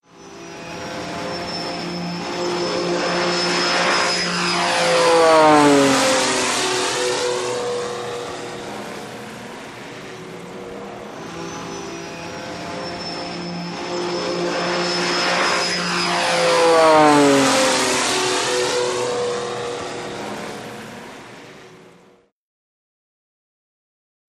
WW2 Fighters|P-38|Single
Airplane P-38 Pass By Fast Twice With Prop Whistle Right To Left Then Left To Right